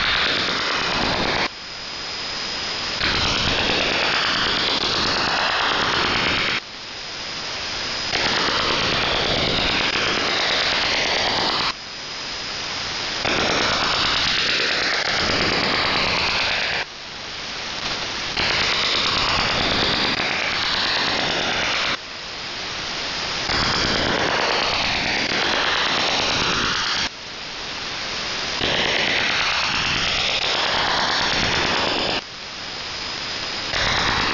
Начало » Записи » Радиоcигналы на опознание и анализ
РЭБ сигнал